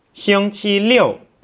(Click on any Chinese character to hear it pronounced.